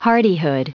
Prononciation du mot hardihood en anglais (fichier audio)